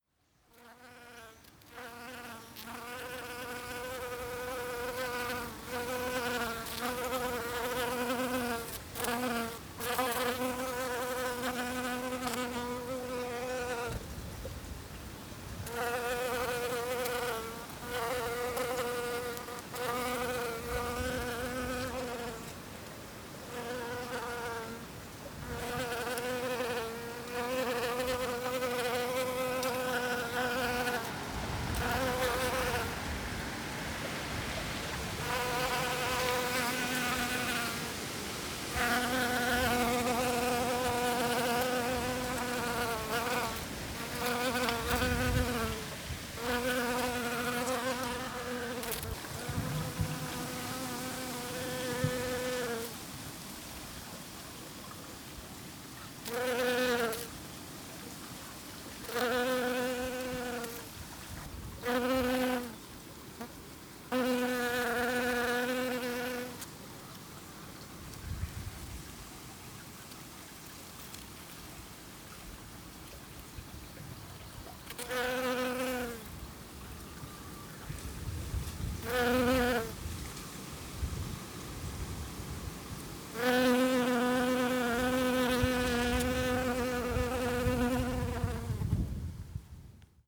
ミツバチの羽音
オオイヌノフグリで蜜を集めるセイヨウミツバチ。花に留まっているは数秒で、花から花へと移っていく。